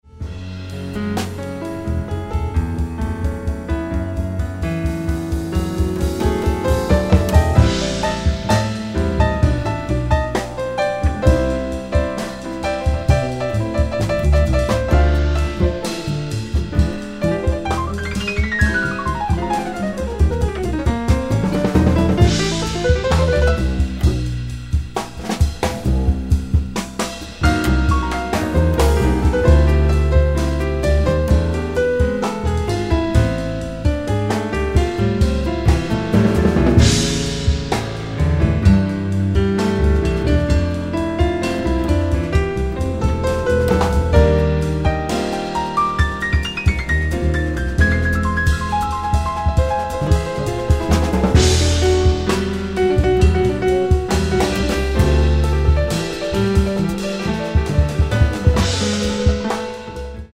piano trio
sounds like a French Folk song powered by slow Rock rhythms